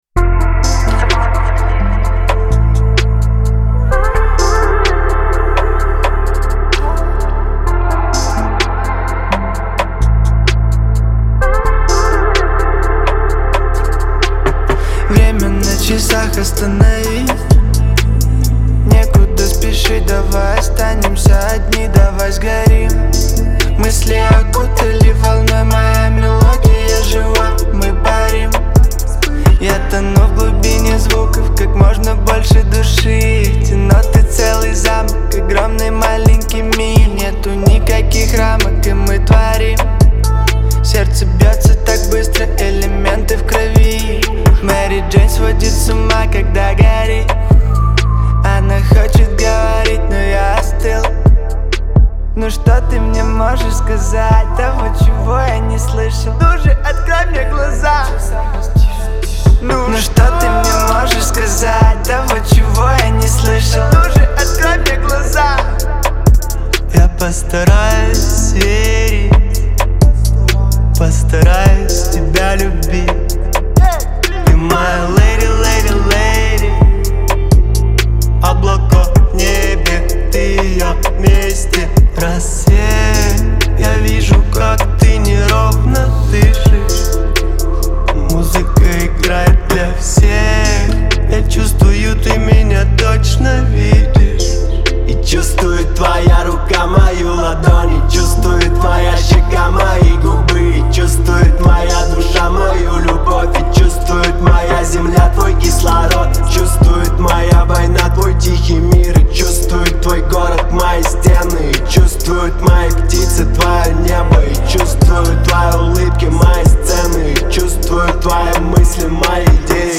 это трек в жанре электронной музыки